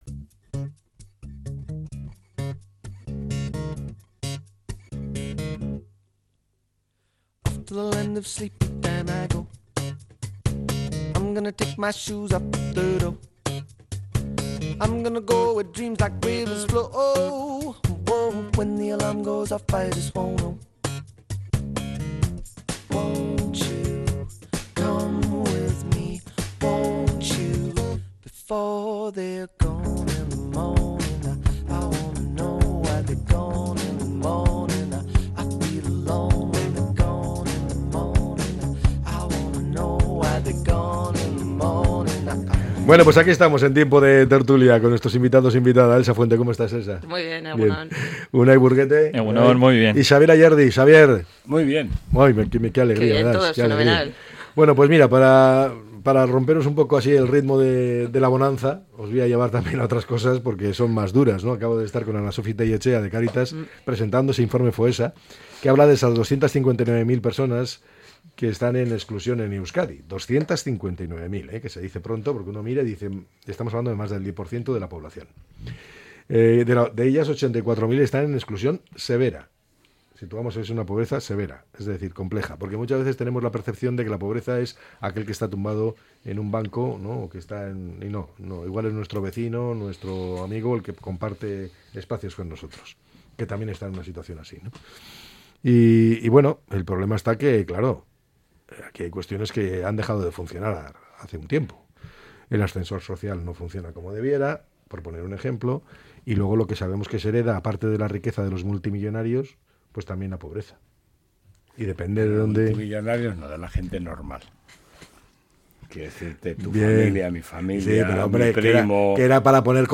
analiza a diario diferentes temas de actualidad con sus tertulianxs